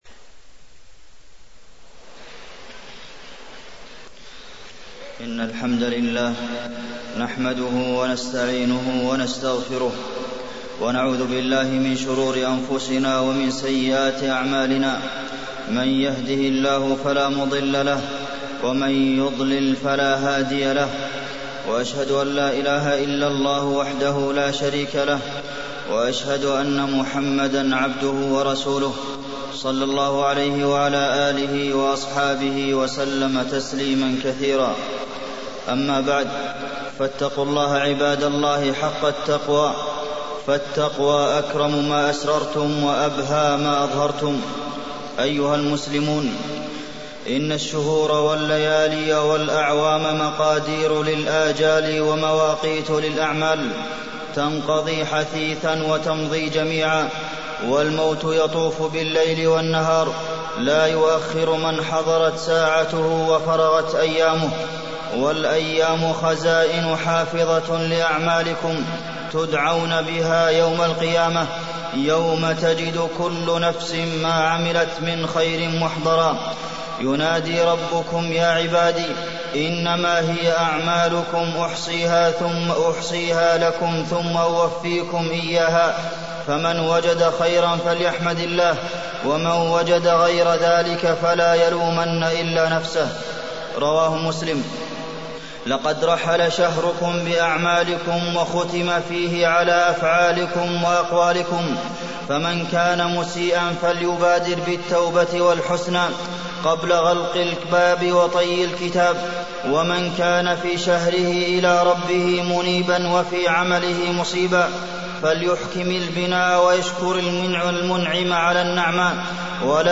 تاريخ النشر ٢ شوال ١٤٢٣ هـ المكان: المسجد النبوي الشيخ: فضيلة الشيخ د. عبدالمحسن بن محمد القاسم فضيلة الشيخ د. عبدالمحسن بن محمد القاسم الأعمال ما بعد شهر رمضان